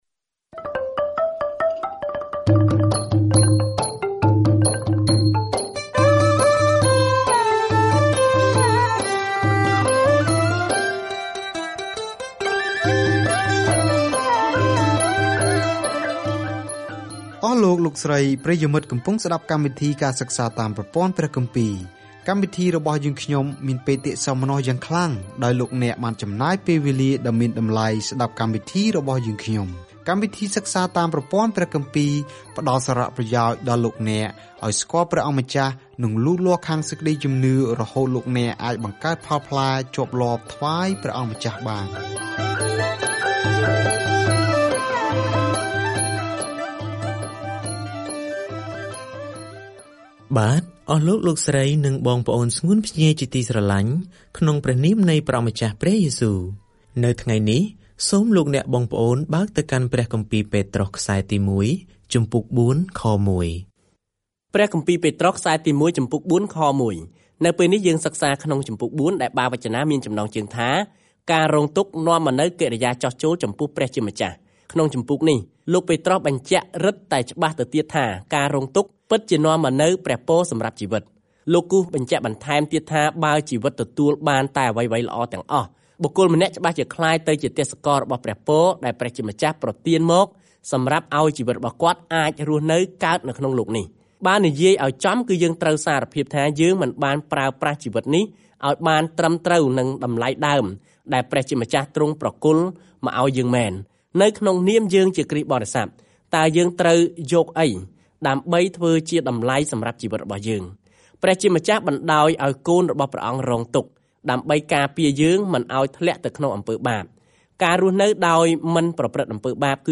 ប្រសិនបើអ្នករងទុក្ខជំនួសព្រះយេស៊ូវ នោះសំបុត្រទីមួយពីពេត្រុសលើកទឹកចិត្ដអ្នកថា អ្នកកំពុងដើរតាមគន្លងរបស់ព្រះយេស៊ូវ ដែលបានរងទុក្ខជំនួសយើងមុន។ ការធ្វើដំណើរប្រចាំថ្ងៃតាមរយៈ ពេត្រុស ទី១ នៅពេលអ្នកស្តាប់ការសិក្សាអូឌីយ៉ូ ហើយអានខគម្ពីរដែលជ្រើសរើសចេញពីព្រះបន្ទូលរបស់ព្រះ។